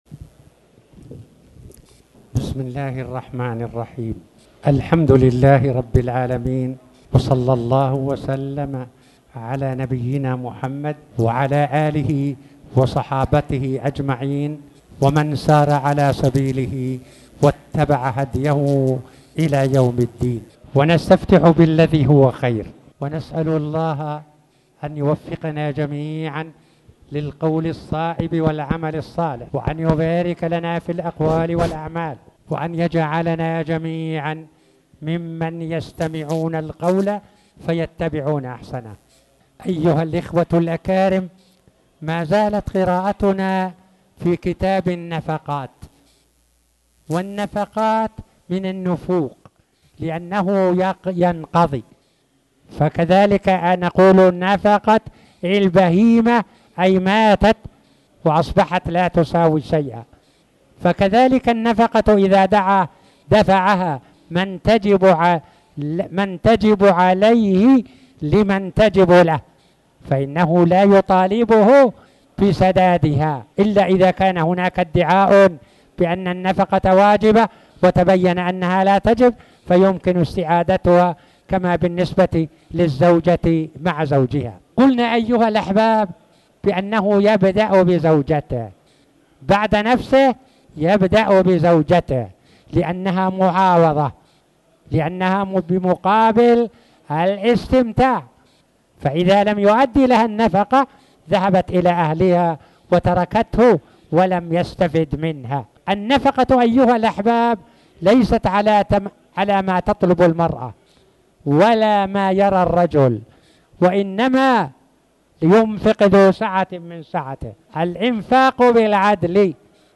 تاريخ النشر ٢٣ جمادى الآخرة ١٤٣٩ هـ المكان: المسجد الحرام الشيخ